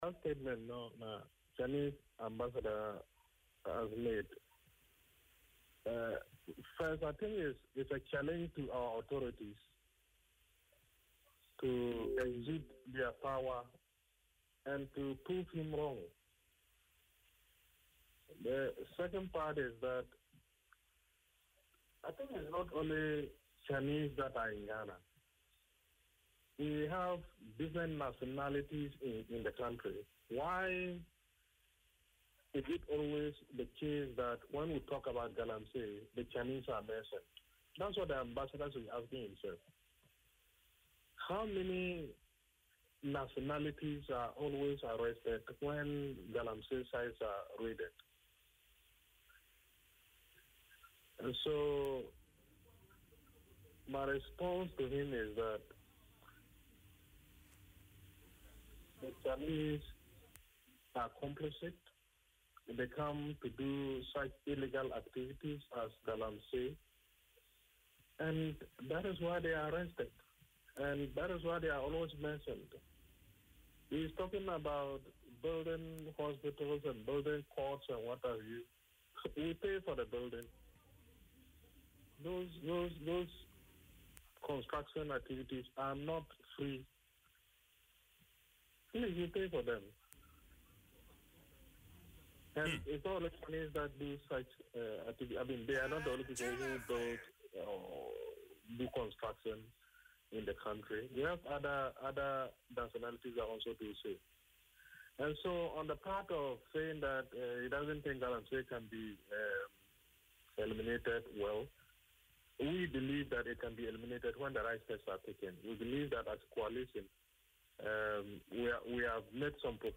The member of the Ghana Coalition Against Galamsey (GCAG) made these remarks during an interview on Adom FM’s Dwaso Nsem.